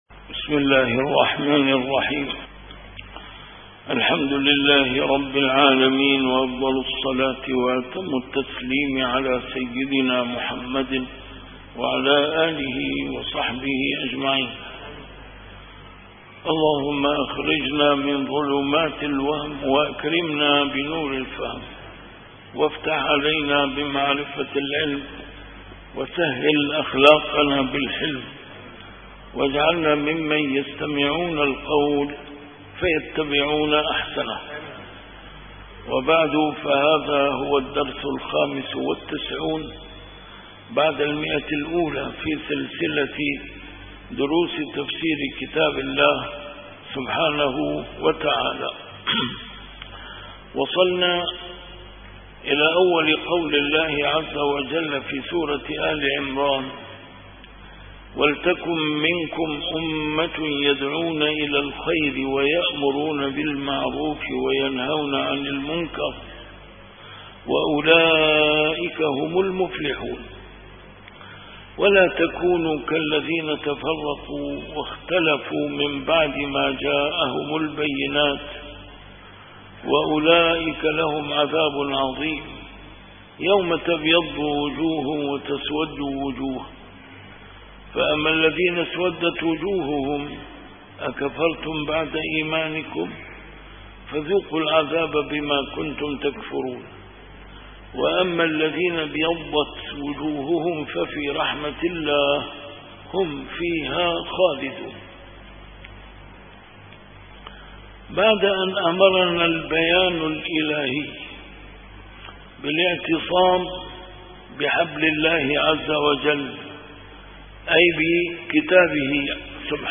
A MARTYR SCHOLAR: IMAM MUHAMMAD SAEED RAMADAN AL-BOUTI - الدروس العلمية - تفسير القرآن الكريم - تفسير القرآن الكريم / الدرس الخامس والتسعون بعد المائة: سورة آل عمران: الآية 104 - 105